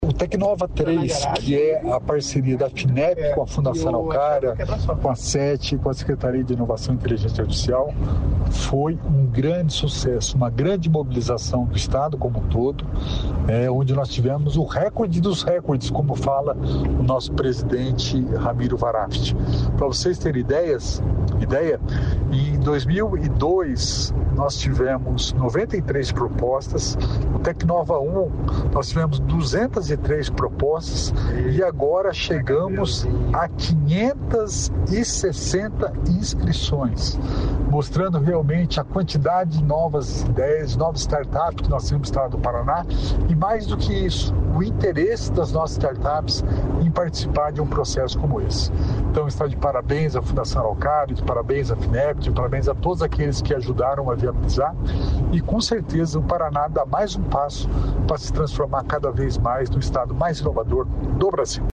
Sonora do secretário da Inovação e Inteligência Artificial, Alex Canziani, sobre o Tecnova III